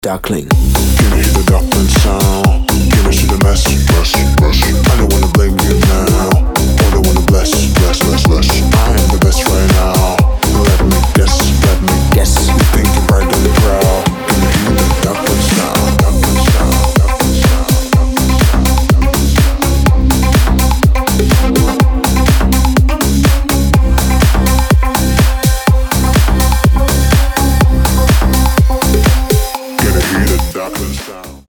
• Качество: 320, Stereo
deep house
EDM
future house
басы
Bass House
качающие